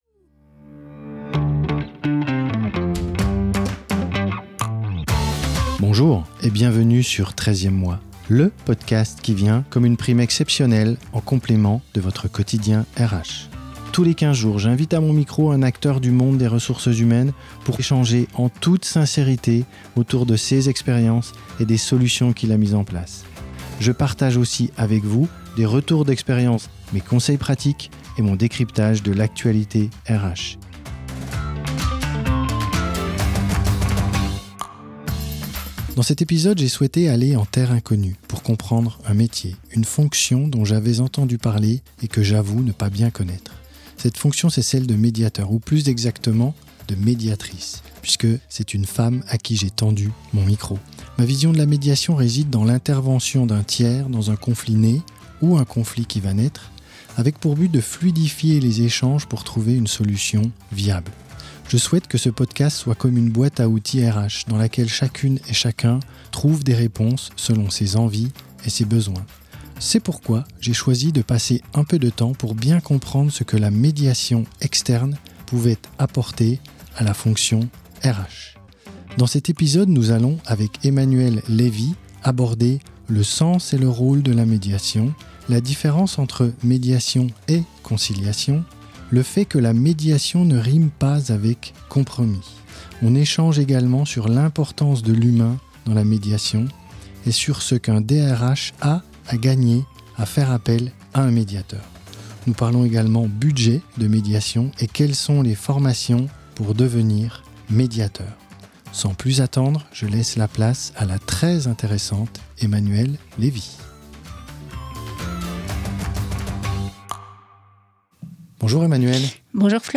Cette fonction c’est celle de médiateur , ou plus exactement de médiatrice puisque c’est une femme à qui j’ai tendu mon micro.